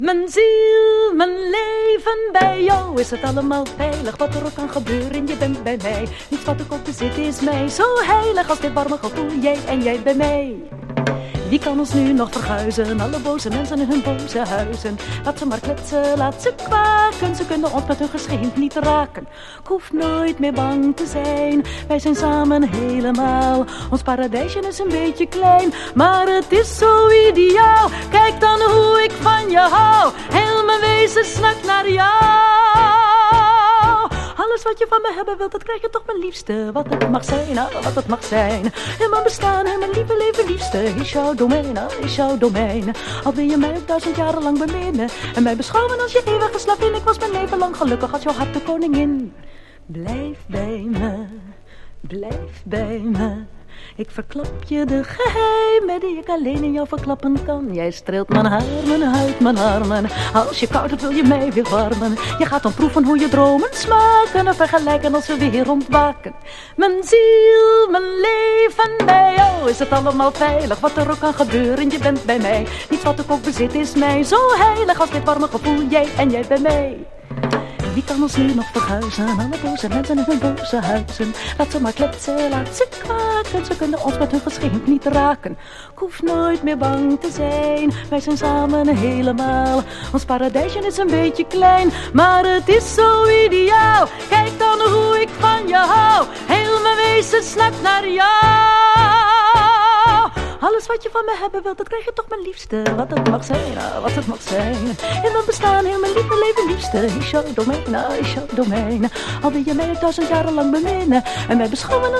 ダッチ・フィメール・シンガー！